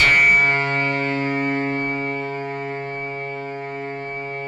RESMET C#3-R.wav